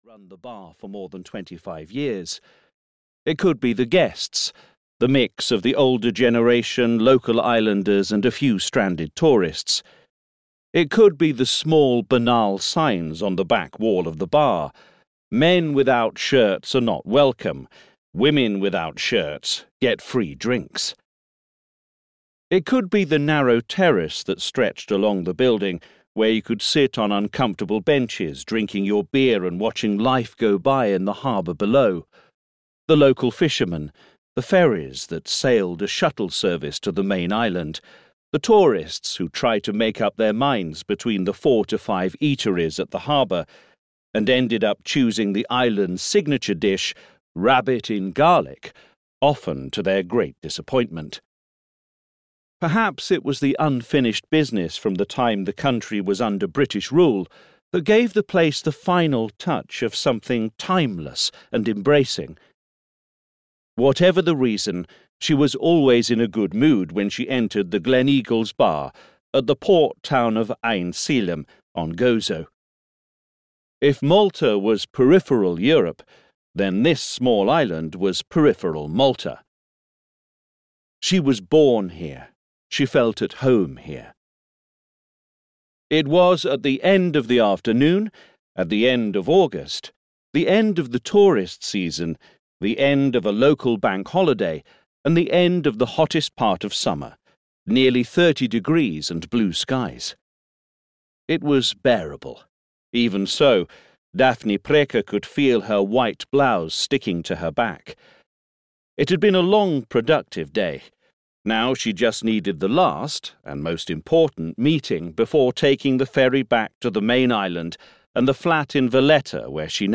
Darklands (EN) audiokniha
Ukázka z knihy